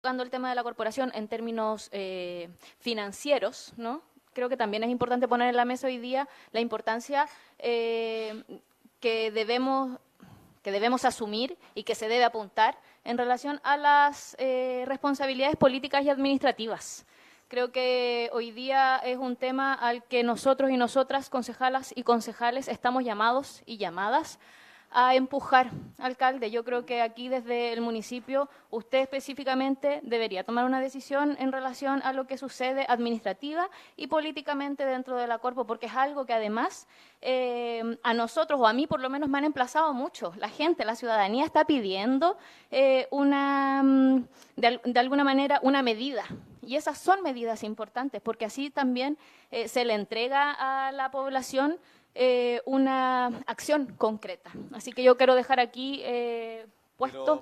Así lo indicó la concejala Rayén Pojomovsky en la última sesión del concejo municipal de La Serena realizada este mièrcoles de manera presencial, como no ocurría hace mucho tiempo.